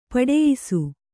♪ paḍeyisu